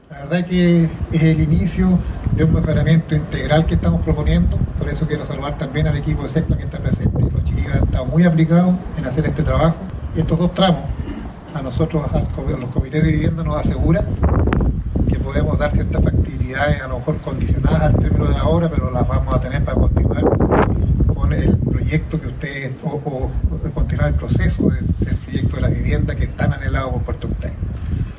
El alcalde de la comuna lacustre, Gerardo Gunckel, señaló que esta obra permitirá levantar proyectos habitacionales que beneficiarán a la comunidad.